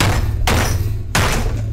Xqc Desk Slam 2 Sound Effect Free Download
Xqc Desk Slam 2